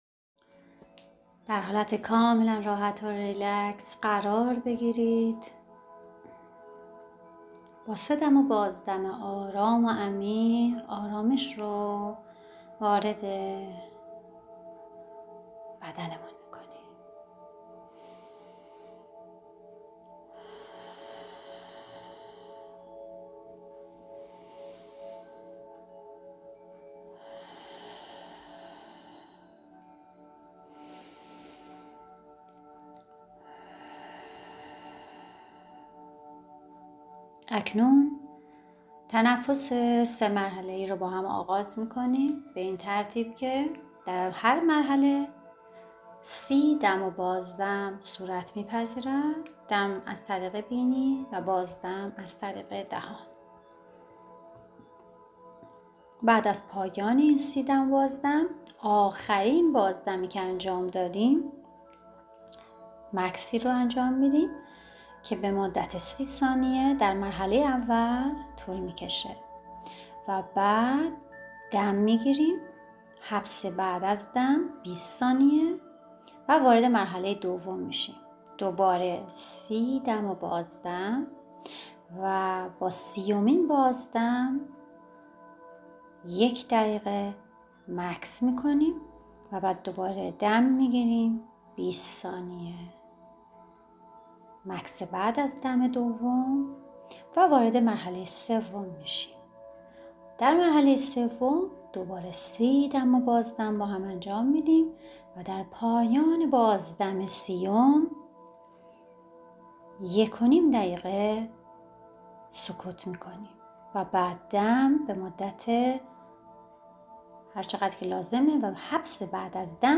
مراقبه تنفسی سه مرحله ای
این مراقبه دارای 3 مرحله است که در هر مرحله 30 دم و بازدم صورت می پذیرد :